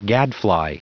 Prononciation du mot gadfly en anglais (fichier audio)